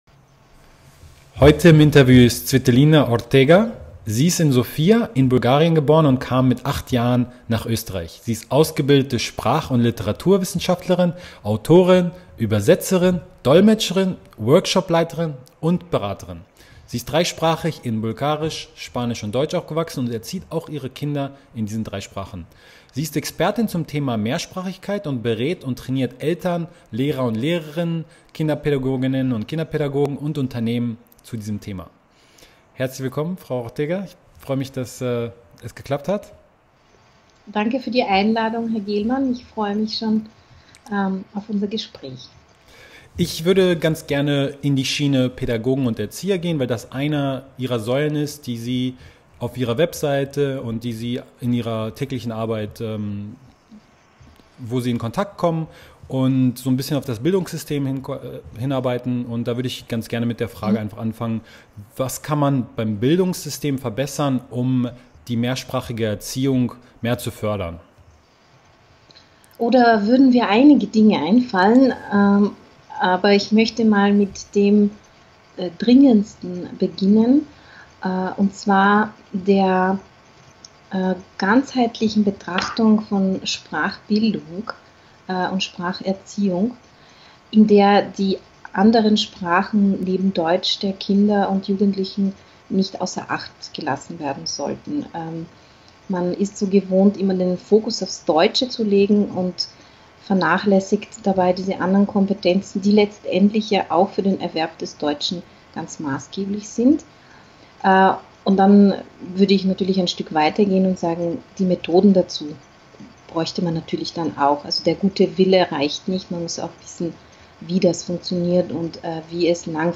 Im Interview sprechen wir über die folgenden Themen: Welchen Beitrag können Pädagogen in Kindergarten und Schule leisten, um die Mehrsprachigkeit bei mehrsprachig aufwachsenden Kindern zu fördern? Wie Eltern die Arbeit der Pädagogen zusätzlich unterstützen können?